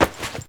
foley_combat_fight_grab_throw_04.wav